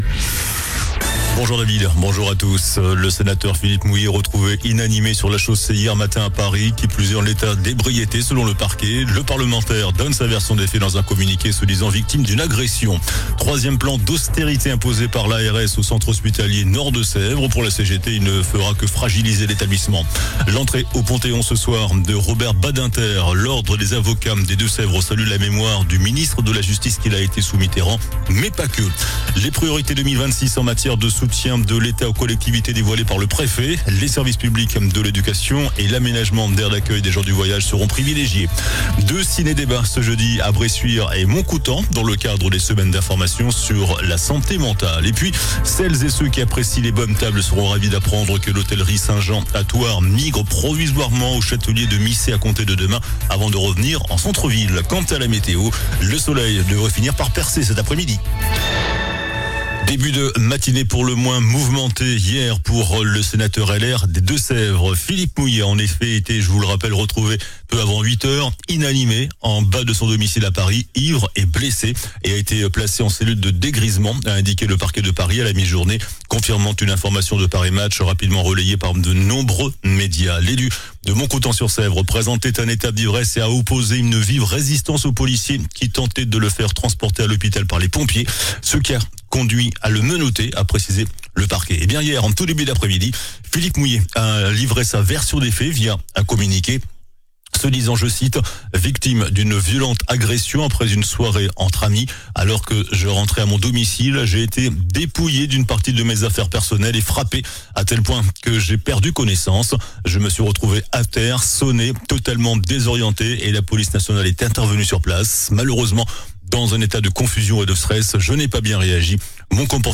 JOURNAL DU JEUDI 09 OCTOBRE ( MIDI )